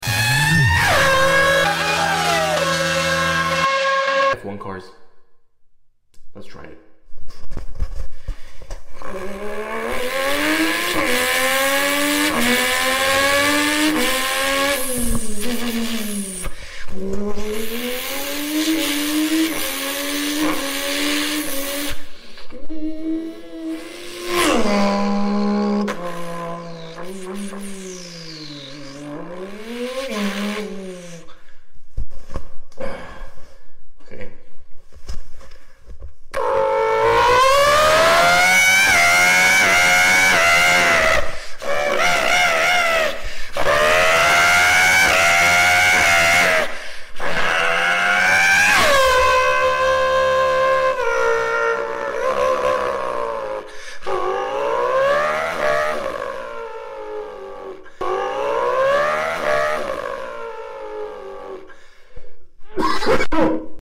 Real F1 Sounds… No Car Sound Effects Free Download